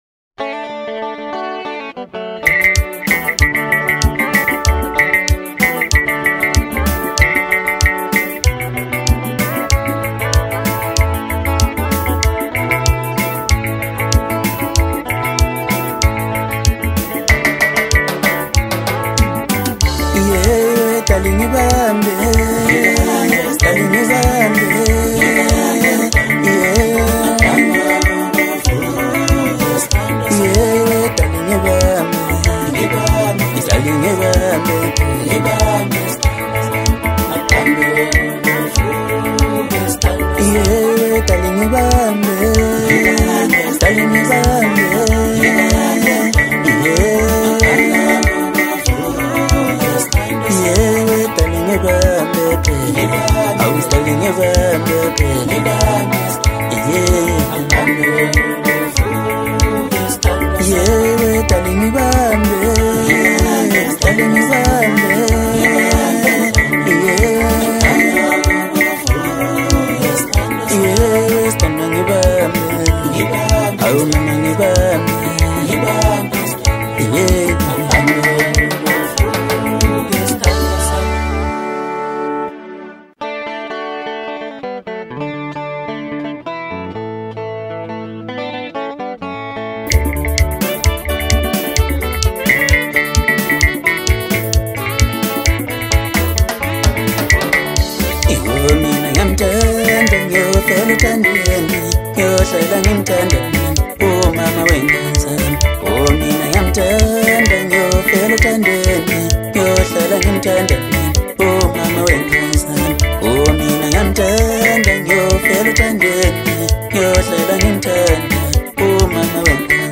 Home » Hip Hop » DJ Mix » Maskandi
South African singer